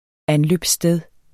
Udtale [ ˈanløbs- ]